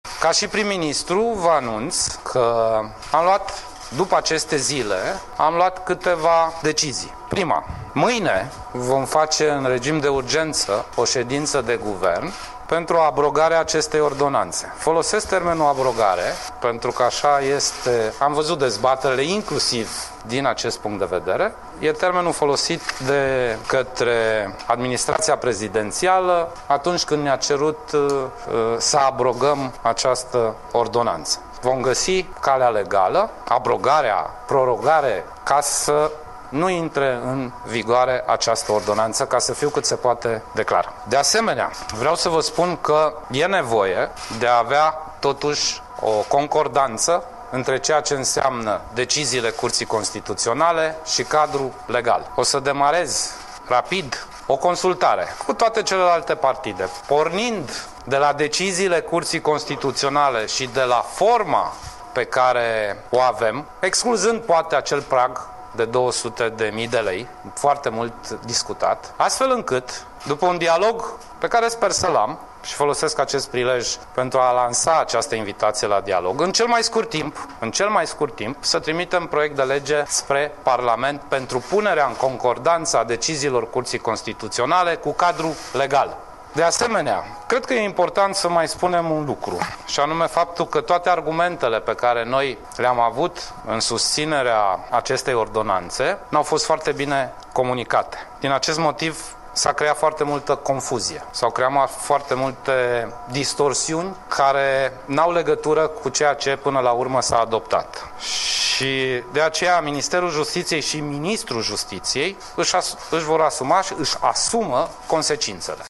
Declarația premierului Sorin Grindeanu (fragmente), 4 februarie 2017